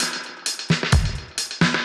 Index of /musicradar/dub-designer-samples/130bpm/Beats
DD_BeatA_130-01.wav